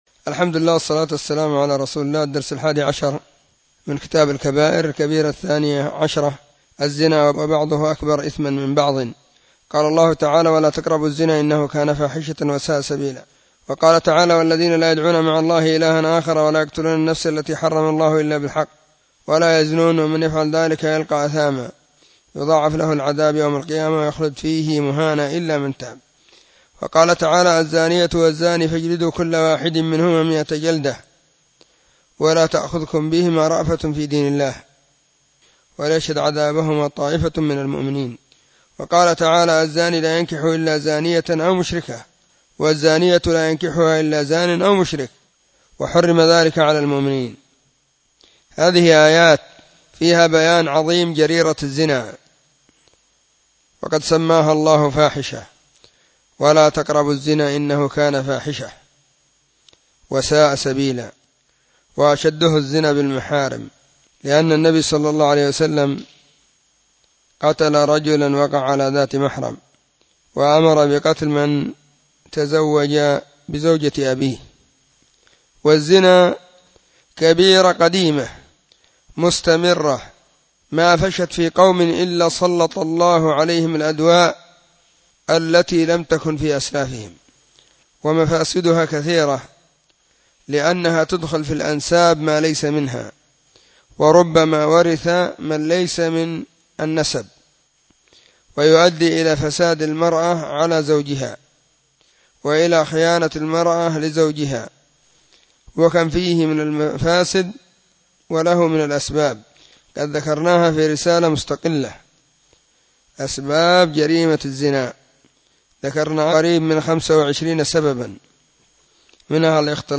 📢 مسجد الصحابة – بالغيضة – المهرة، اليمن حرسها الله.…
🕐 [بين مغرب وعشاء – الدرس الثاني]